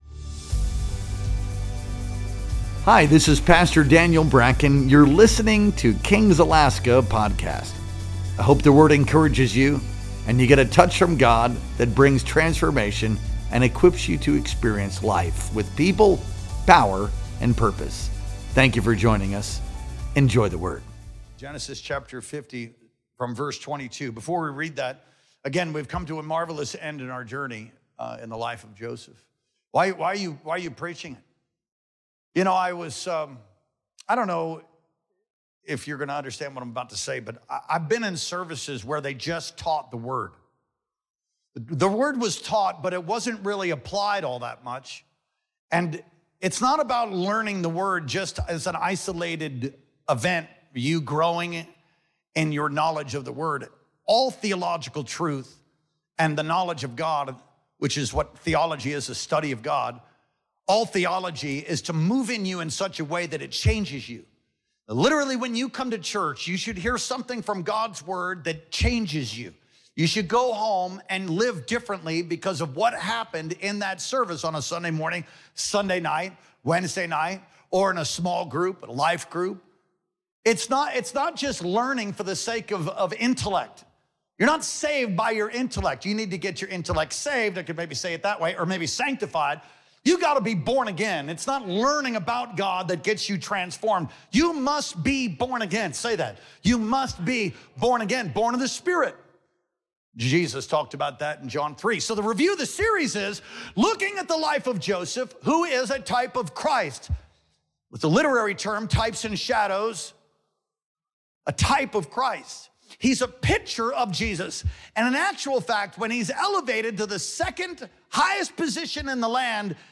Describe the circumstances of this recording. Our Sunday Worship Experience streamed live on July 20th, 2025.